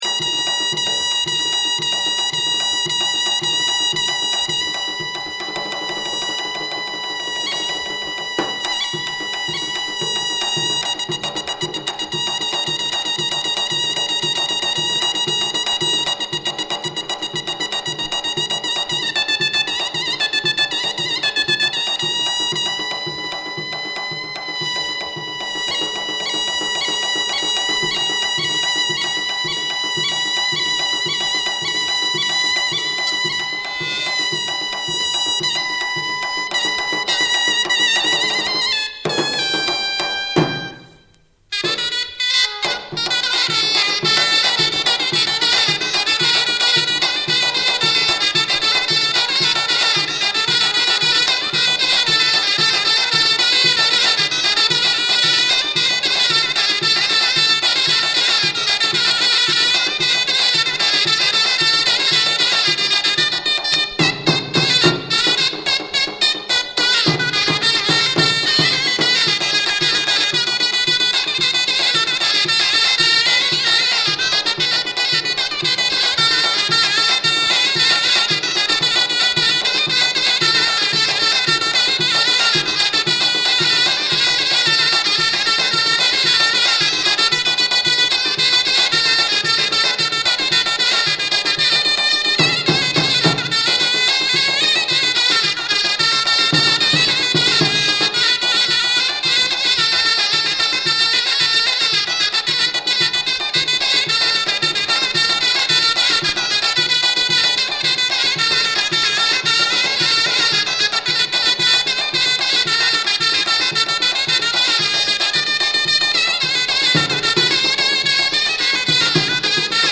エジプトの伝統音楽を記録。ナイル流域の音楽家たちによる演奏を収めたフィールド録音作品。